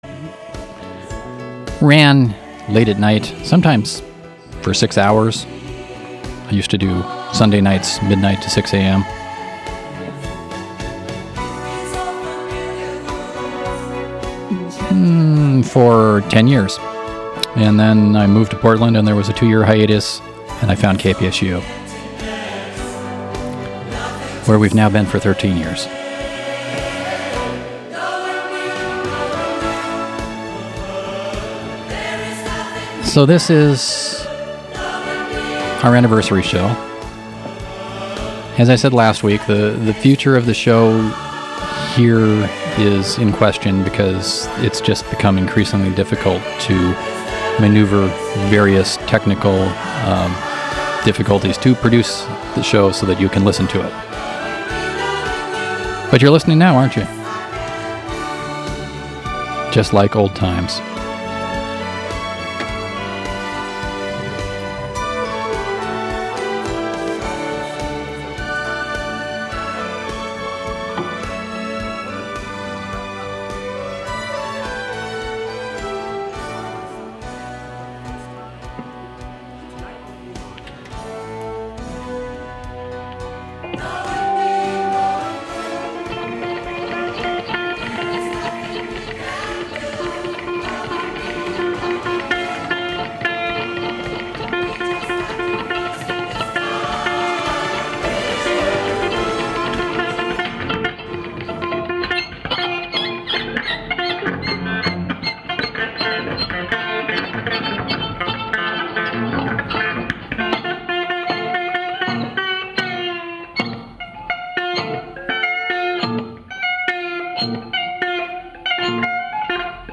This episode was an all cd affair, just like it was when it launched in ’93!